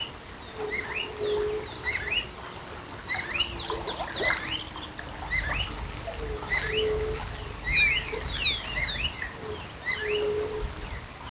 Green-backed Becard (Pachyramphus viridis)
Sex: Male
Province / Department: Entre Ríos
Condition: Wild
Certainty: Photographed, Recorded vocal
Anambe-verdoso.mp3